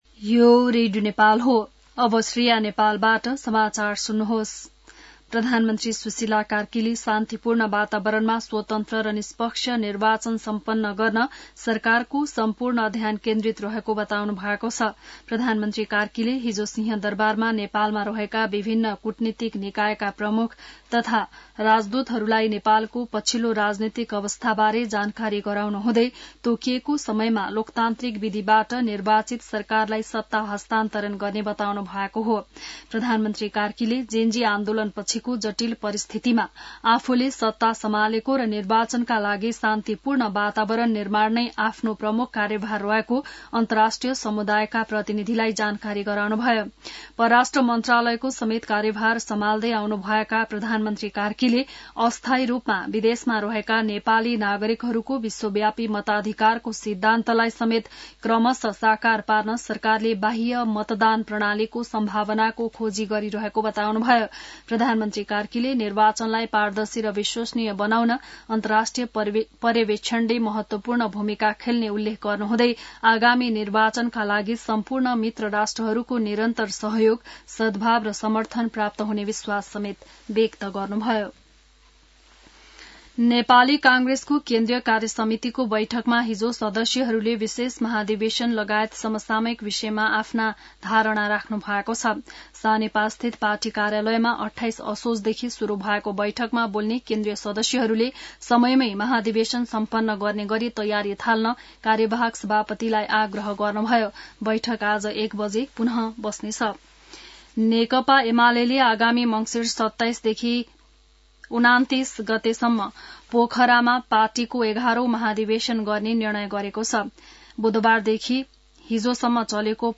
बिहान १० बजेको नेपाली समाचार : १ कार्तिक , २०८२